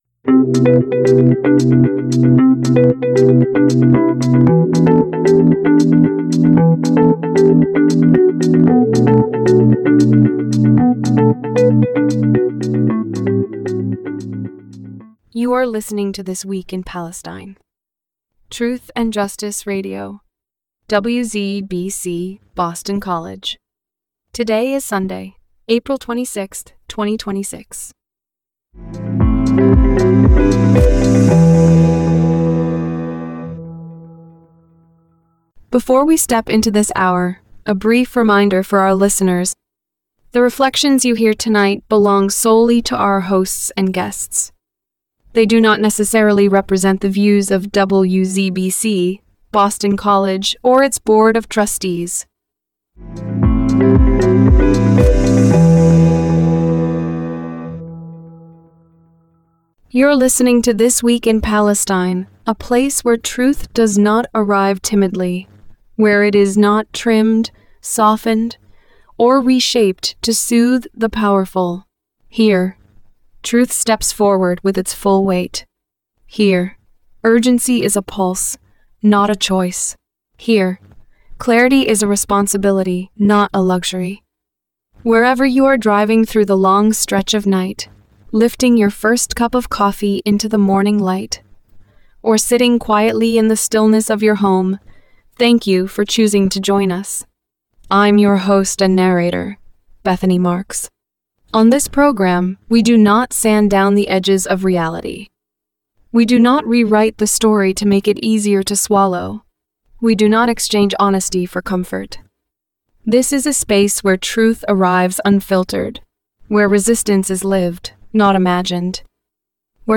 TWIP-260426 Subtitle: TWIP-260426 Who Moves the Markets, and Who Pays the Price. Program Type: Weekly Program Speakers